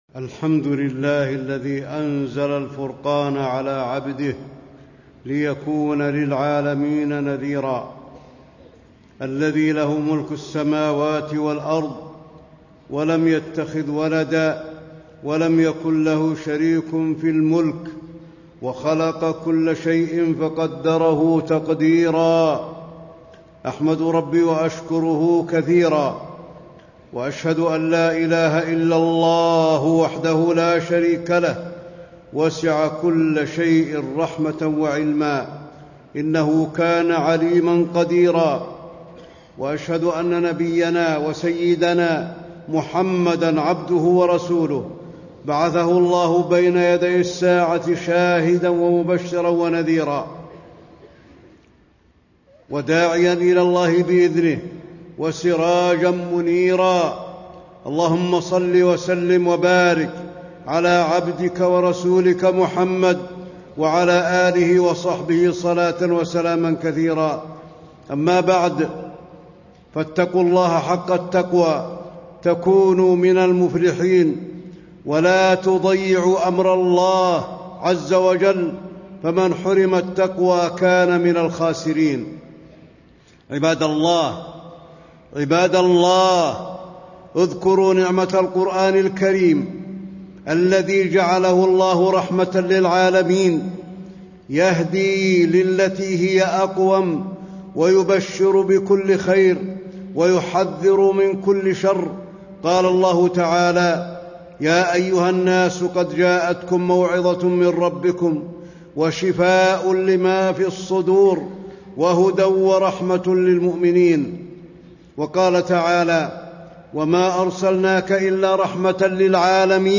تاريخ النشر ٢٤ رجب ١٤٣٥ هـ المكان: المسجد النبوي الشيخ: فضيلة الشيخ د. علي بن عبدالرحمن الحذيفي فضيلة الشيخ د. علي بن عبدالرحمن الحذيفي تعظيم القرآن الكريم في النفوس